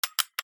M73 撃鉄 M73 Hummer 01
/ H｜バトル・武器・破壊 / H-05 ｜銃火器
『カチャ』